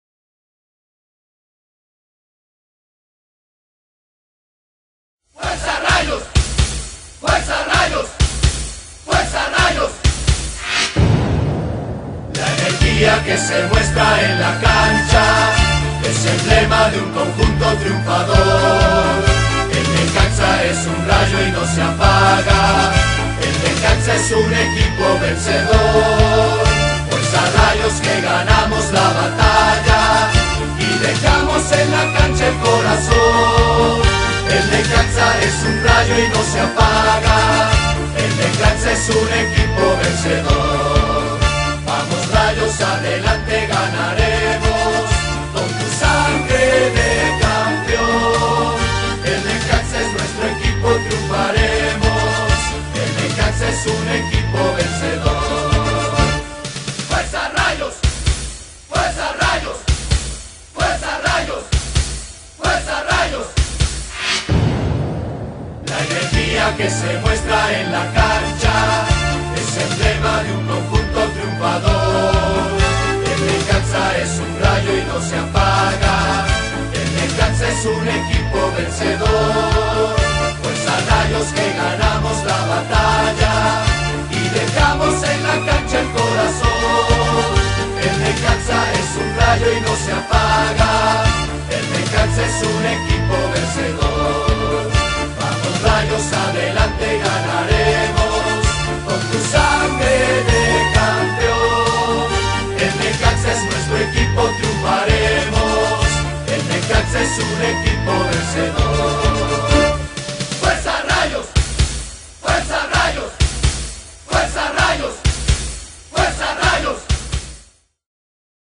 Himnos